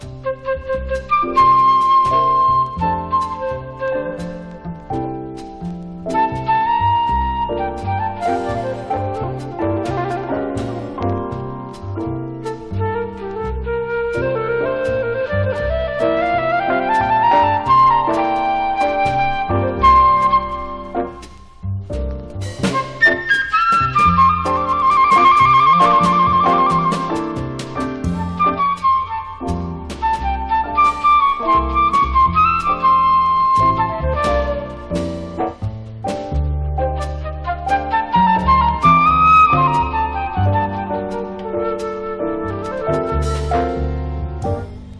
Jazz, Bop, Modal, Cool Jazz　USA　12inchレコード　33rpm　Stereo